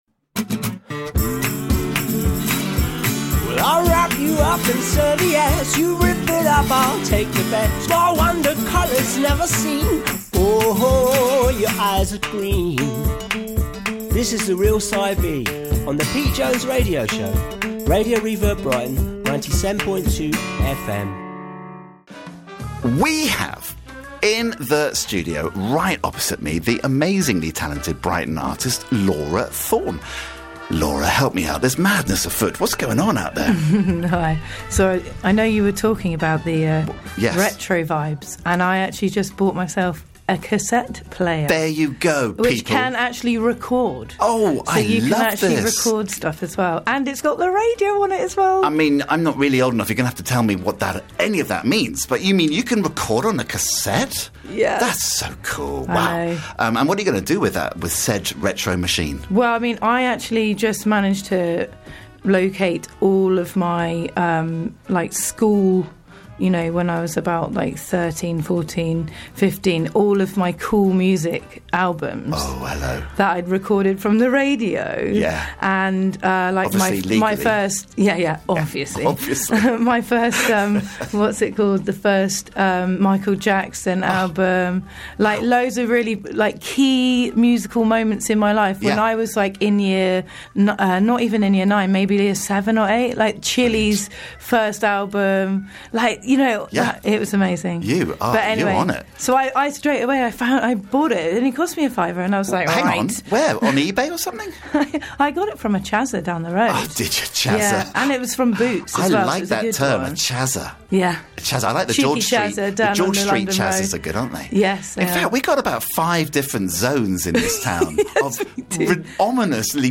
Live chat